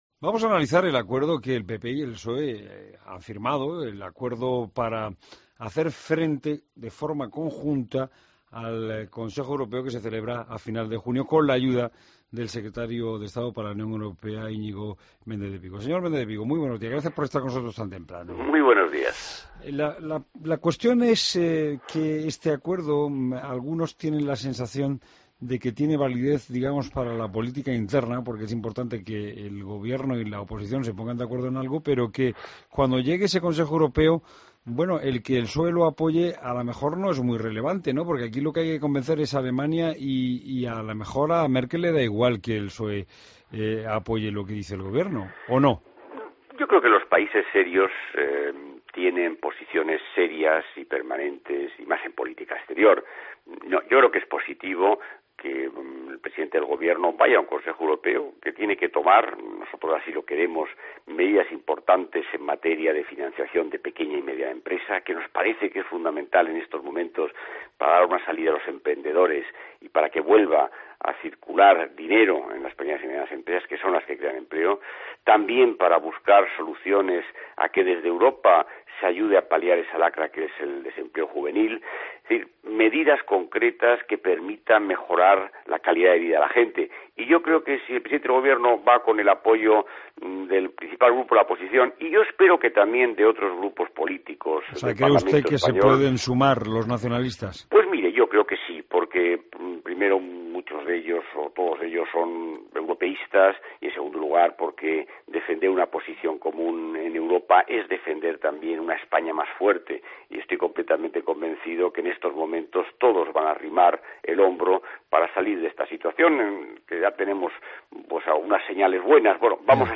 Entrevista a Íñigo Méndez de Vigo, secretario de Estado para la UE